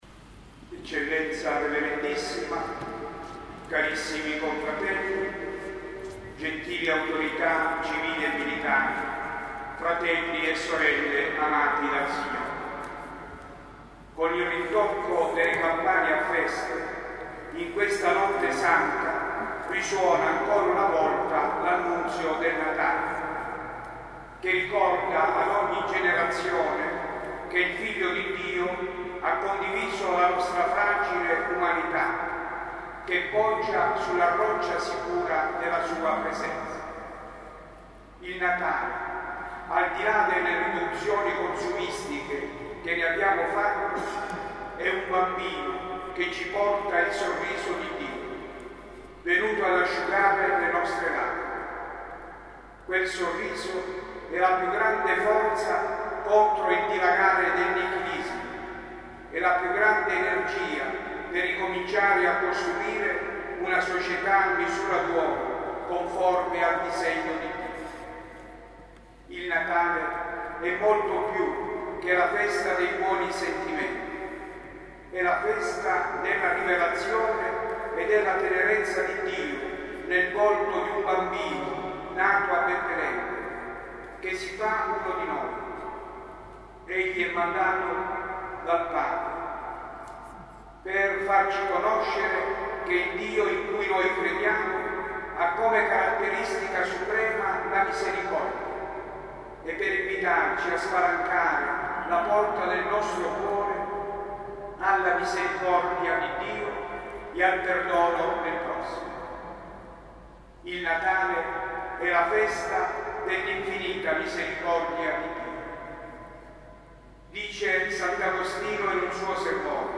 Multimedia : Audio/Audio2015/Notte di Natale/13-Omelia - Duomo di Monreale
13-Omelia.mp3